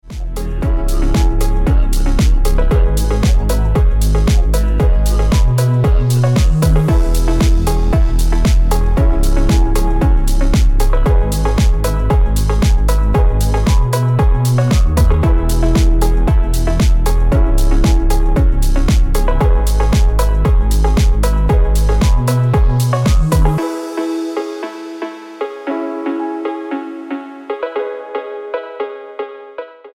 Phonk Music